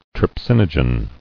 [tryp·sin·o·gen]